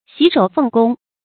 洗手奉公 注音： ㄒㄧˇ ㄕㄡˇ ㄈㄥˋ ㄍㄨㄙ 讀音讀法： 意思解釋： 見「洗手奉職」。